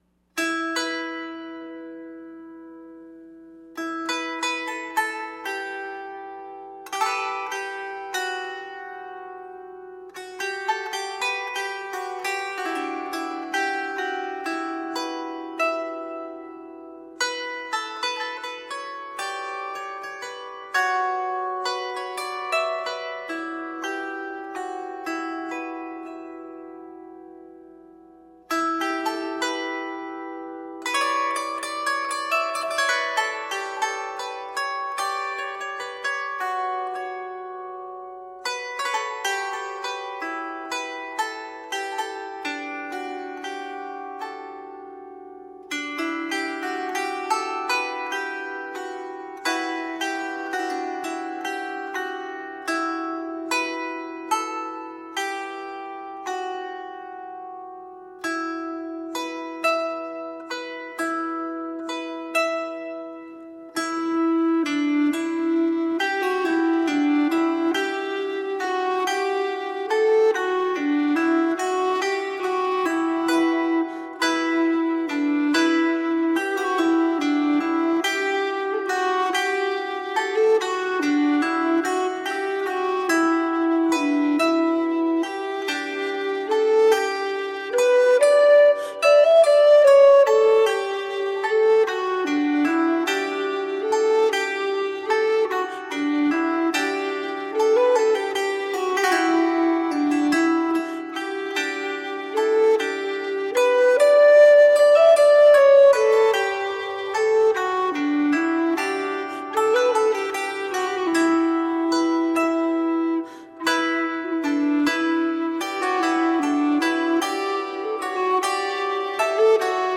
Medieval music from the 12th to the 15th centuries.
medieval instrumental music